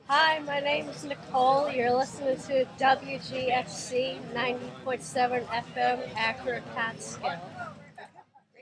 WGXC Station ID
at Athens Street Festival.